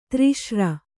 ♪ tri sra